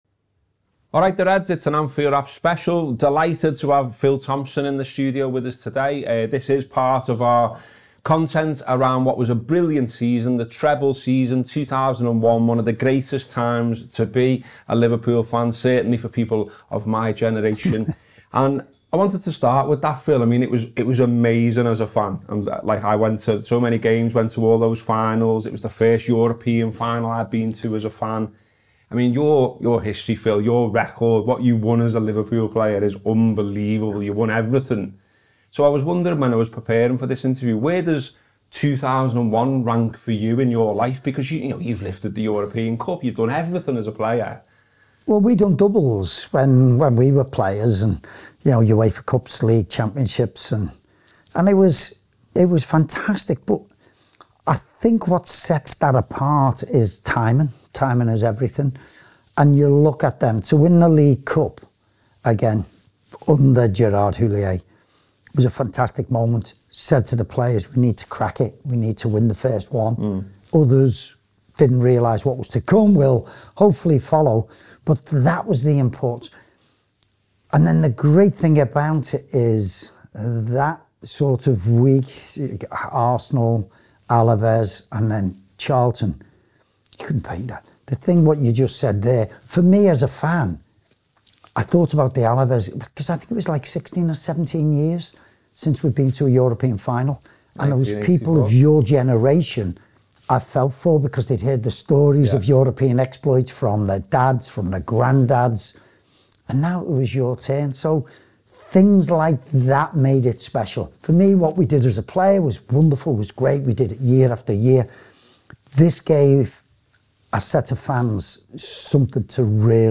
Phil Thompson On Liverpool’s Treble: The Big Interview
Below is a clip from the show – to listen to more of our season reviews, click here to subscribe…